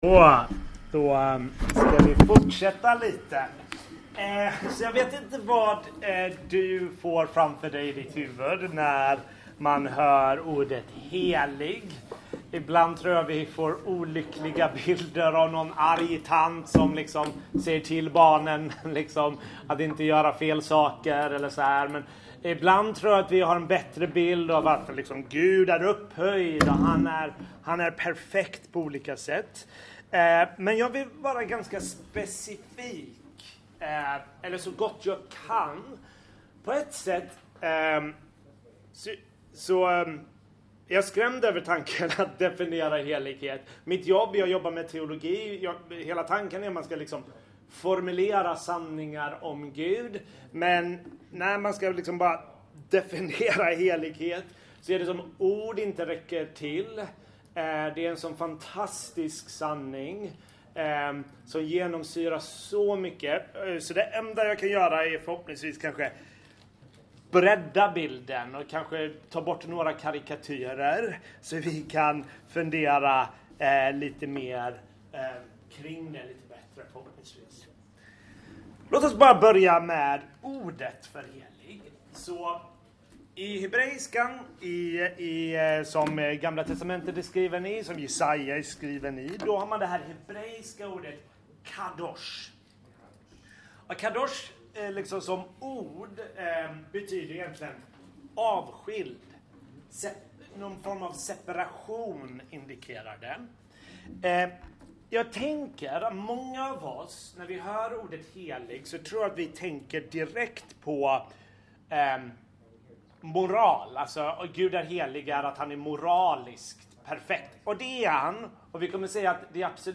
Bibelstudium